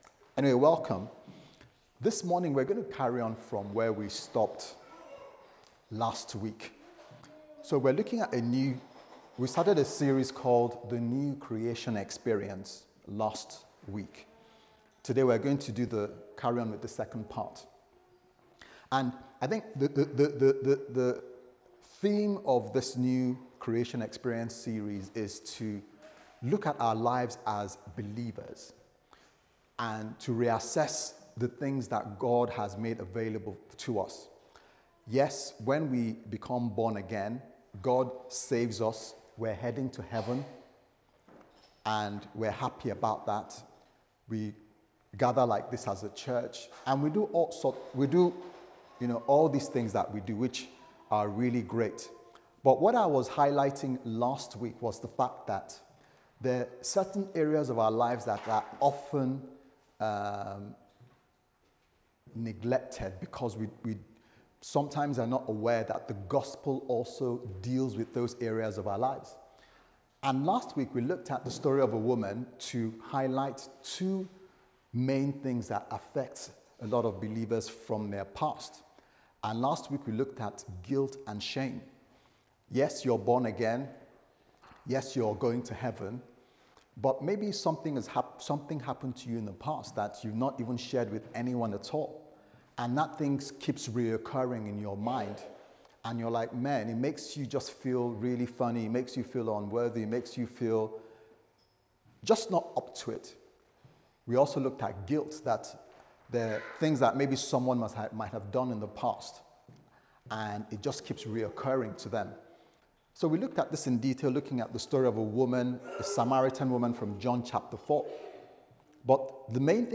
The New Creation Experience Service Type: Sunday Service Sermon « Philipians 4v1-3 The New Creation Experience